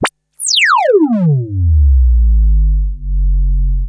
fxpTTE06009sweep.wav